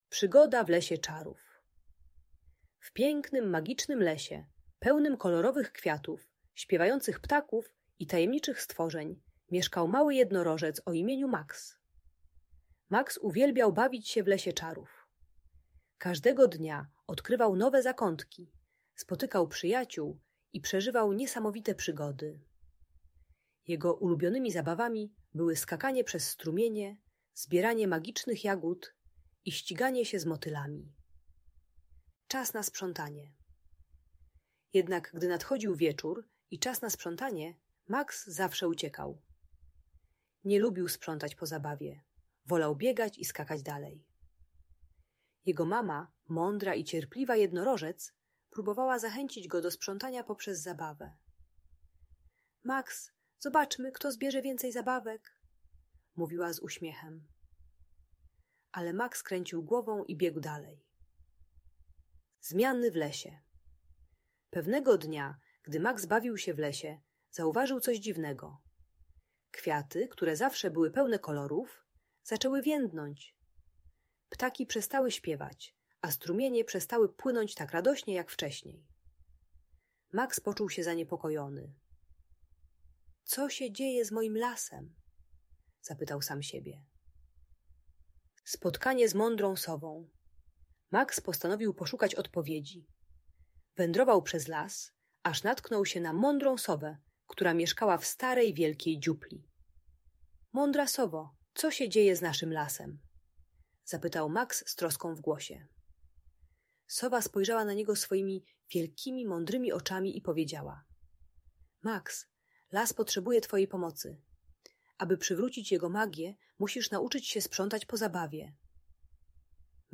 Magiczna historia Maxa w lesie czarów - Audiobajka